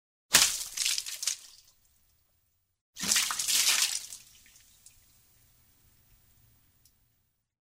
Blutspritzer | Laden Sie Soundeffekte .mp3 herunter.
Blutspritzer | Soundeffekt .mp3 | Gratis herunterladen.